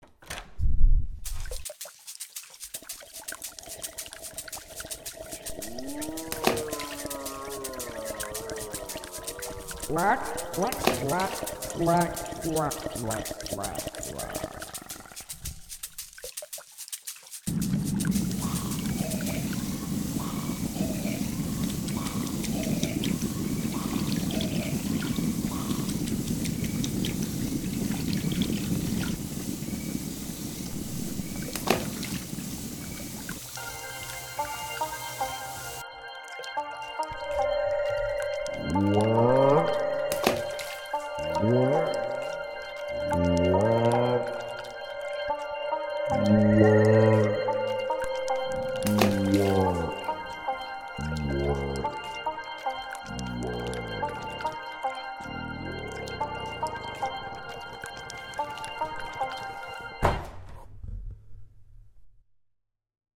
In jedem Raum erlebst du eine Geräuscheatmosphäre mit vielen verschiedenen Geräuschen.
Geraaeuscherei_-_Unterwasserdschungel.mp3